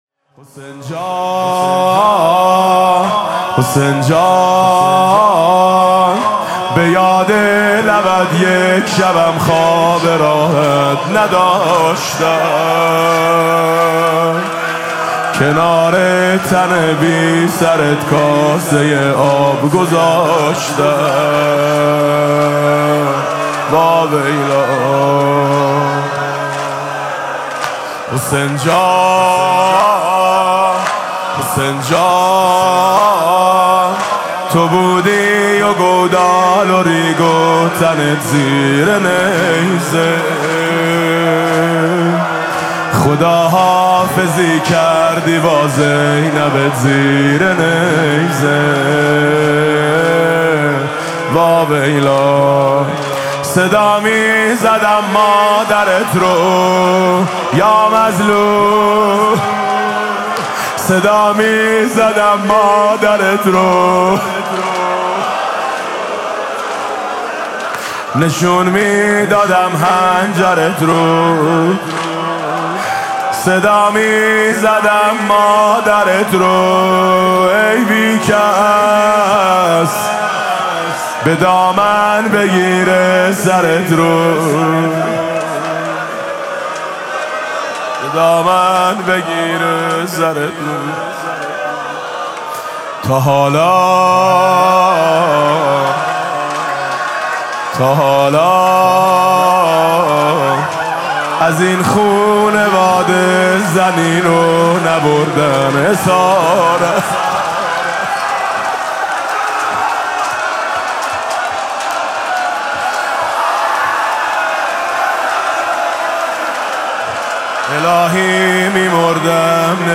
مقتل خوانی عصر عاشورا ۱۴۰۳
با نوای: حاج میثم مطیعی
تا حالا از این خانواده زنی رو نبردن اسارت (زمینه)